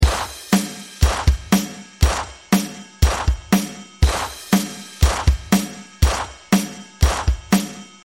因为从音乐本身来说，4/4拍的歌曲，第一拍是强拍，强拍的地方通常会出现低音，这就是为什么你听大部分爵士鼓的低音大鼓都是出现在1、3拍。 例如这个最常用的节奏： 音频： 同时还不难听出，那个相比之下高一点且更有穿透力的小鼓（响弦Snare）声音，都是出现在24拍。